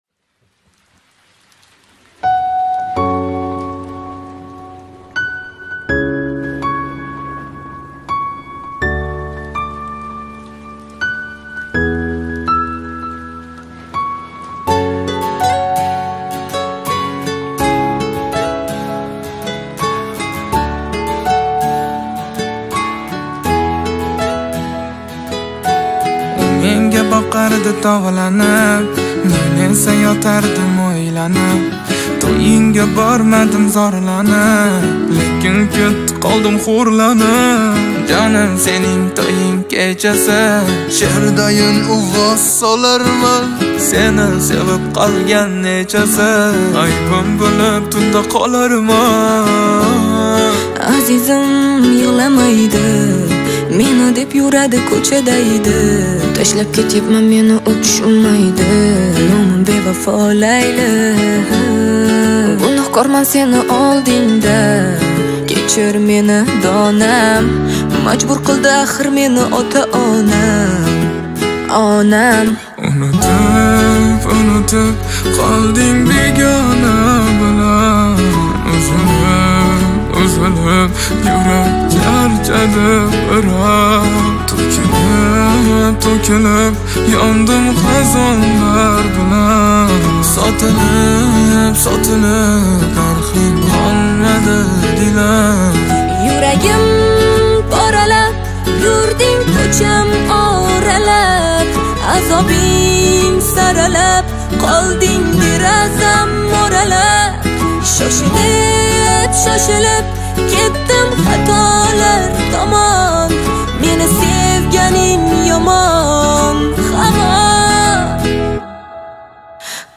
гитара версия
gitara versiya